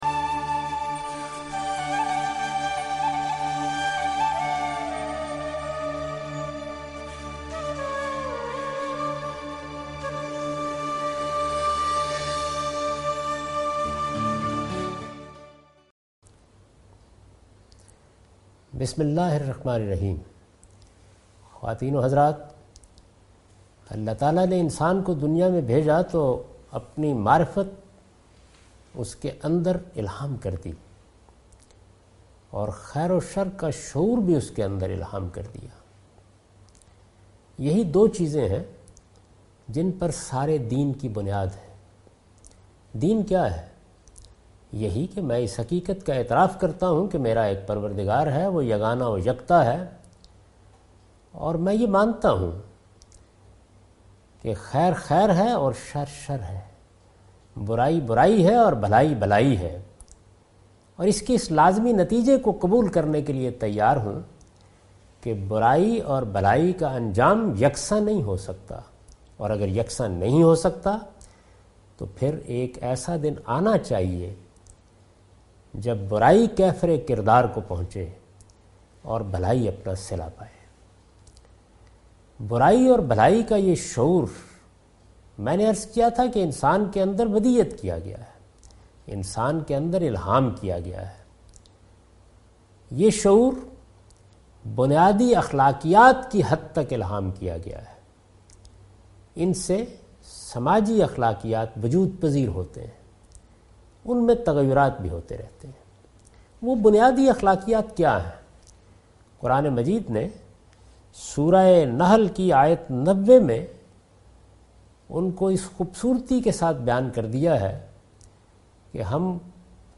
This series contains the lecture of Javed Ahmed Ghamidi delivered in Ramzan.